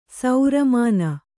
♪ saura māna